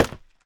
1.21.4 / assets / minecraft / sounds / step / scaffold3.ogg
scaffold3.ogg